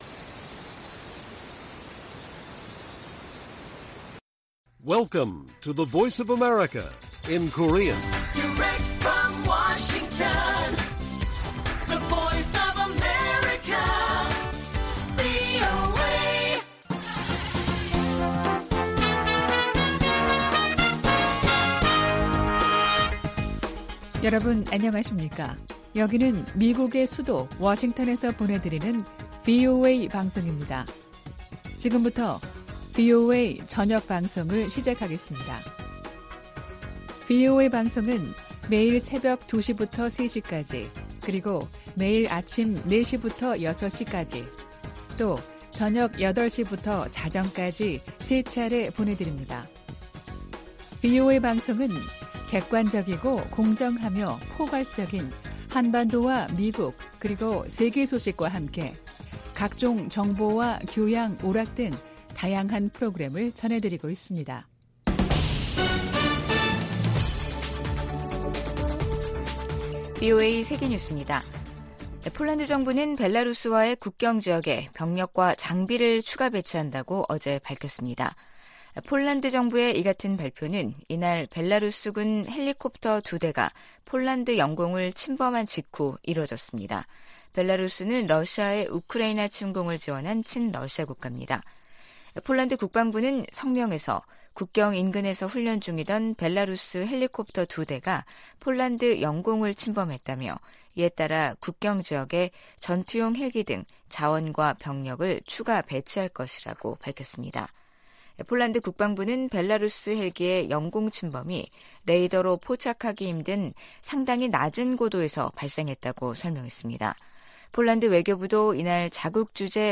VOA 한국어 간판 뉴스 프로그램 '뉴스 투데이', 2023년 8월 2일 1부 방송입니다. 미국은 중국과 러시아가 북한의 행동을 규탄하는 데 동참할 수 있도록 계속 노력할 것이라고 린다 토머스-그린필드 유엔주재 미국대사가 밝혔습니다. 유럽연합(EU)이 북한과 러시아 간 무기 거래의 불법성을 지적하며 중단을 촉구했습니다. 미국 국방부가 미한일 3자 안보 협력 확대를 위해 노력하겠다는 입장을 거듭 확인했습니다.